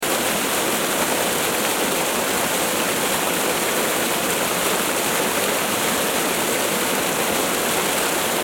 دانلود صدای آبشار 4 از ساعد نیوز با لینک مستقیم و کیفیت بالا
جلوه های صوتی